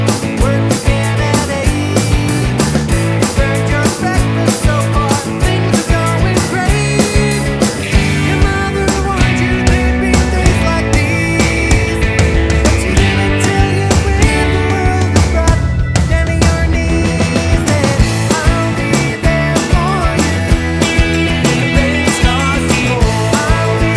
no Backing Vocals T.V. Themes 3:03 Buy £1.50